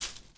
SFX_paso3.wav